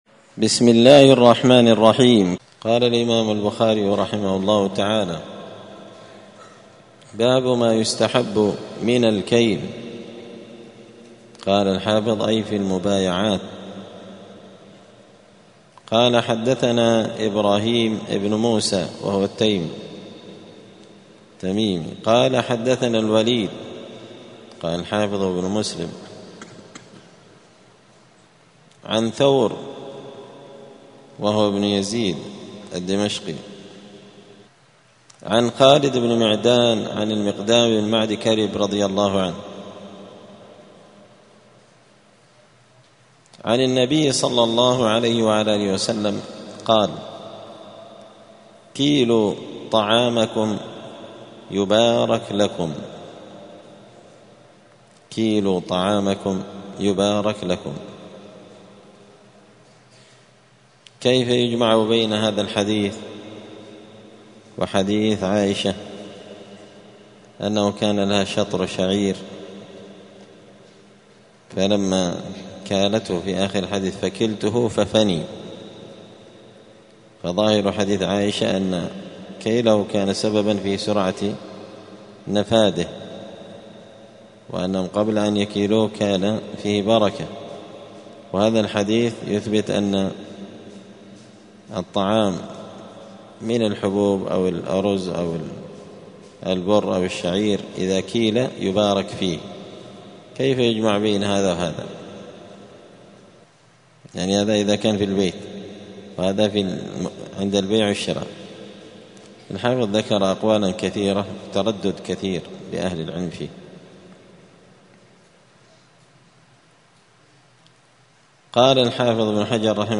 دار الحديث السلفية بمسجد الفرقان قشن المهرة اليمن
الثلاثاء 12 ربيع الثاني 1446 هــــ | الدروس، دروس الحديث وعلومه، شرح صحيح البخاري، كتاب البيوع من شرح صحيح البخاري | شارك بتعليقك | 28 المشاهدات